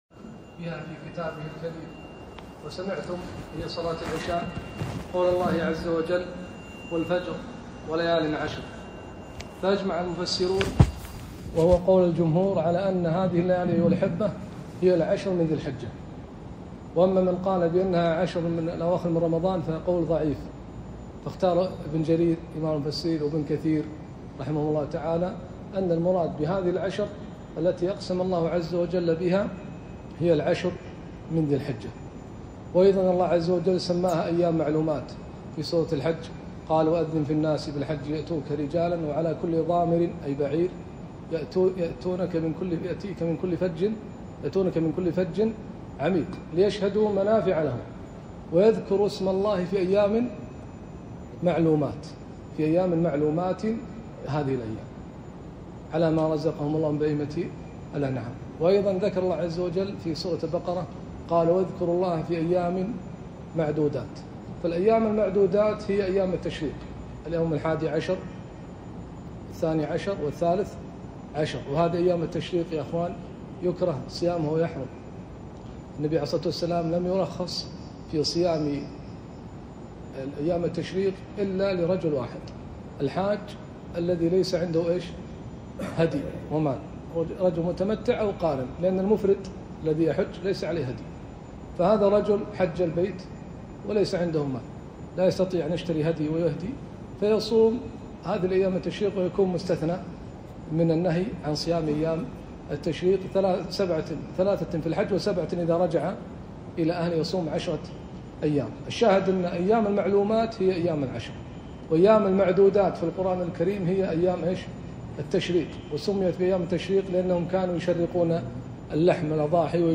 كلمة - فضل عشر ذي الحجة